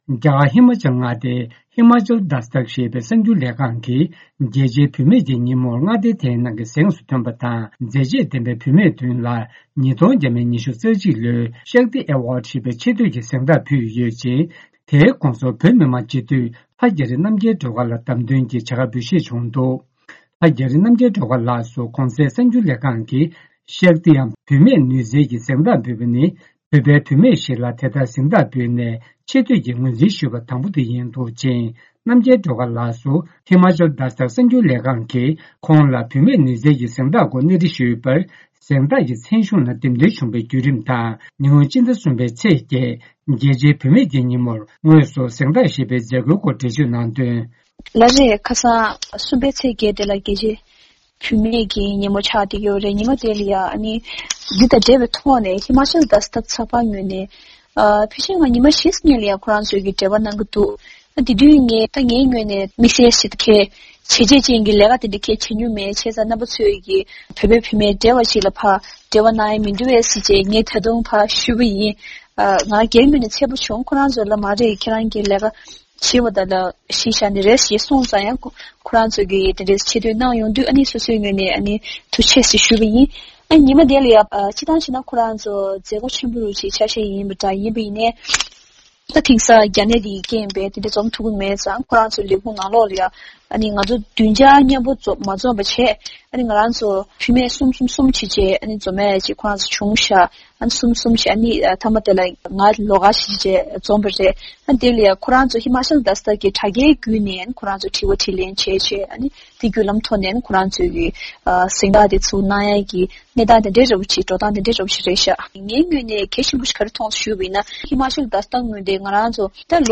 བཅར་འདྲི་ཕྱོགས་སྒྲིག་ཞུས་པ་གསན་རོགས་གནང་།།